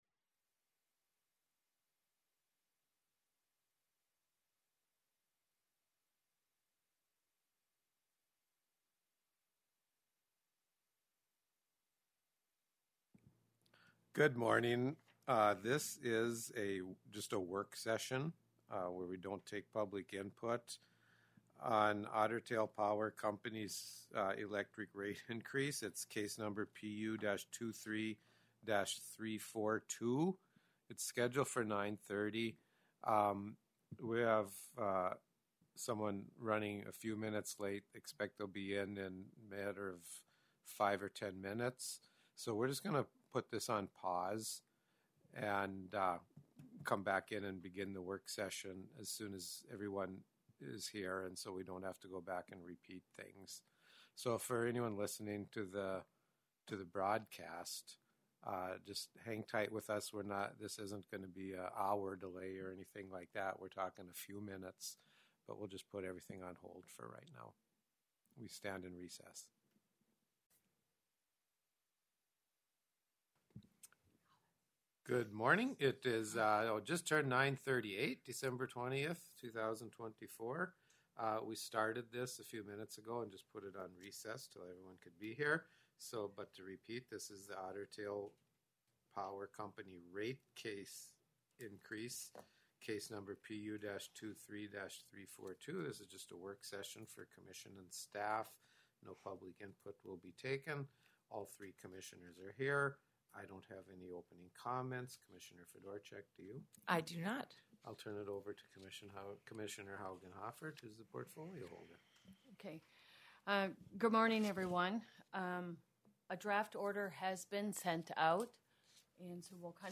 Electronic Recording of 20 December 2024 Work Session